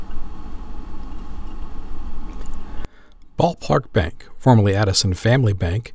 I intentionally boosted the noise in this clip so you can hear it. Not only is there fan or motor sounds, but a tiny tinkling sound?